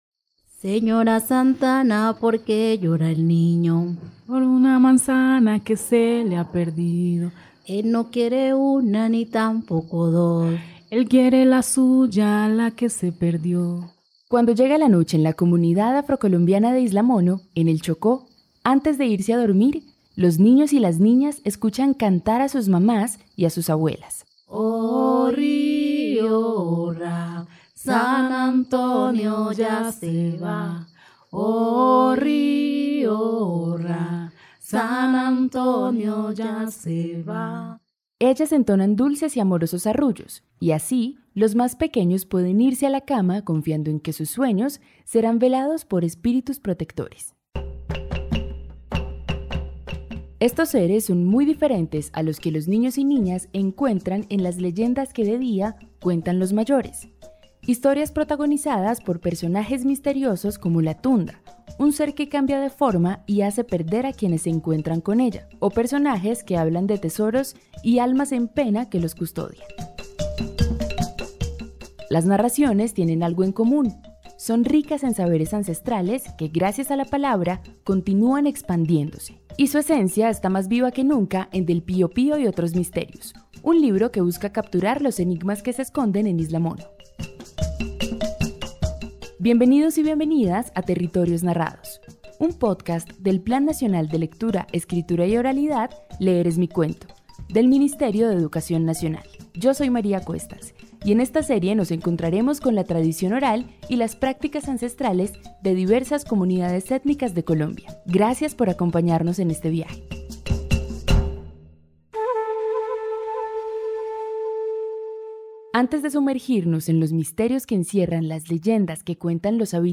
Introducción En este podcast se narran relatos y leyendas de la comunidad afro de Isla Mono. La producción fortalece la tradición oral al conservar historias transmitidas por generaciones mediante la voz y la memoria colectiva.